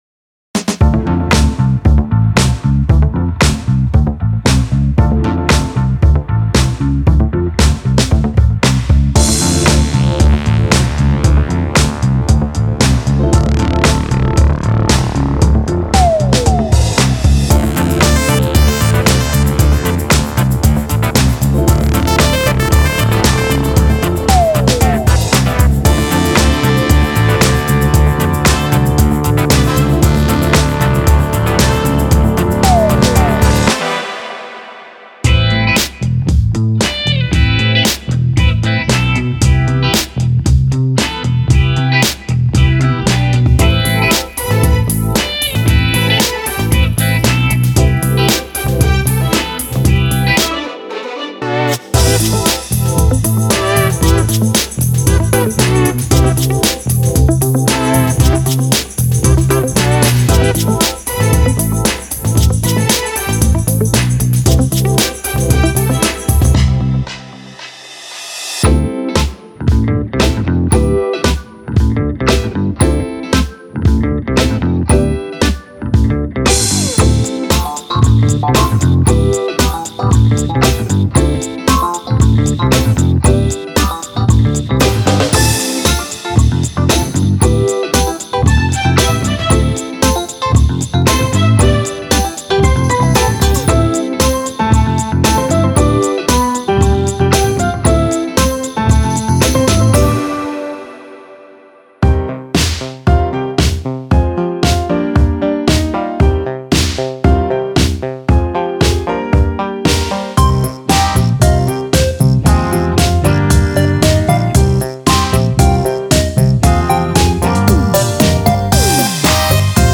Genre:Disco
このマイクロディスコパックには、ベース、ドラム、ギターなどが含まれています。
この小さなパックには大きなパワーが詰まっており、まさに本物の70年代ディスコサウンドを思い起こさせます。
ユーロディスコ、ディスコファンク、そしてソウルです。
ぜひ、さまざまなスタイルをミックスしたディスコのデモをチェックしてください。